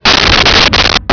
Sfx Whoosh 4901
sfx_whoosh_4901.wav